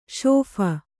♪ śopha